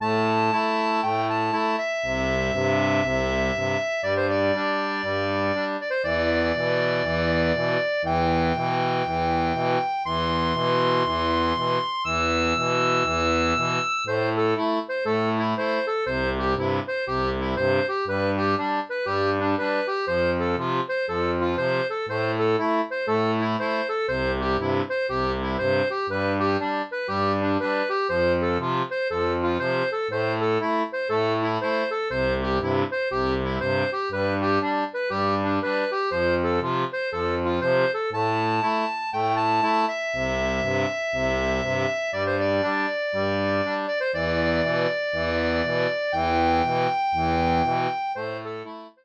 • une tablature pour accordéon diatonique à 2 rangs basique
Folk et Traditionnel